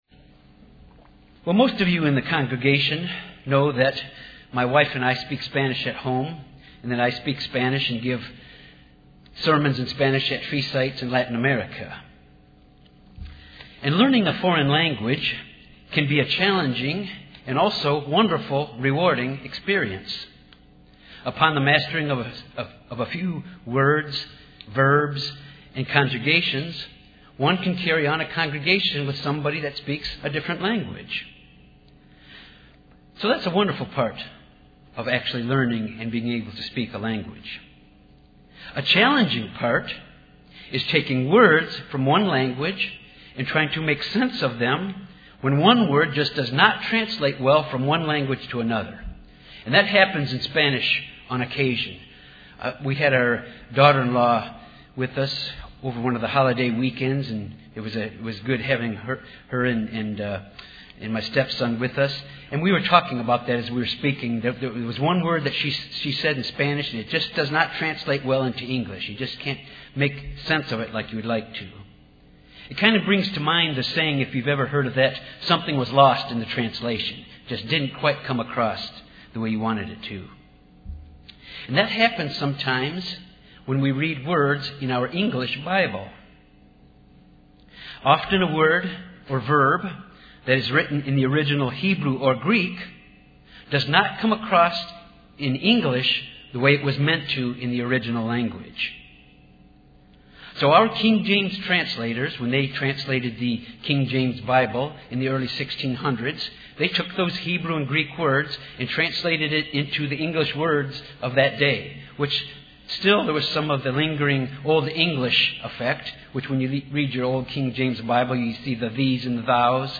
Given in Little Rock, AR Jonesboro, AR
UCG Sermon Studying the bible?